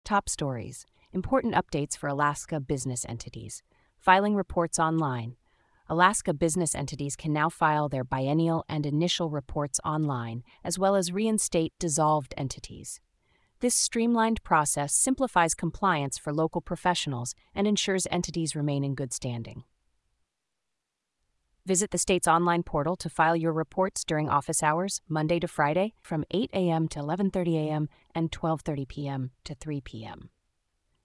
Daily briefing for real estate professionals in Alaska